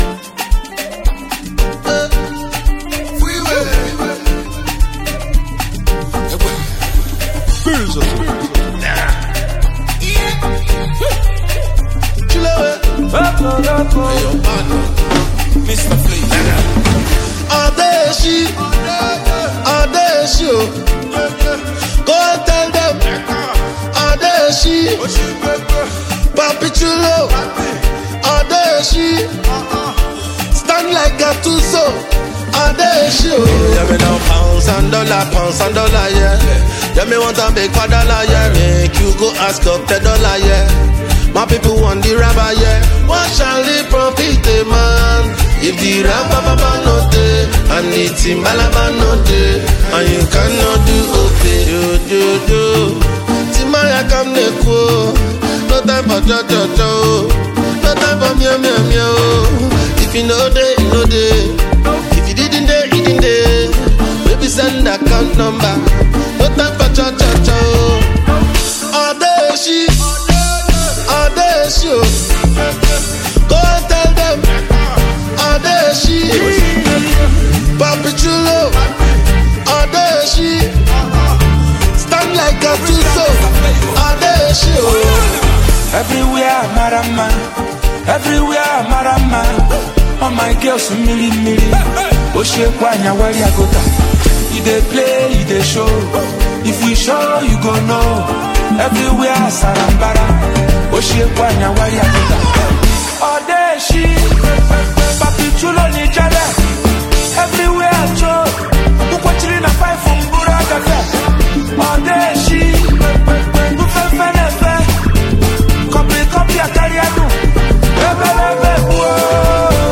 • Genre: Afrobeats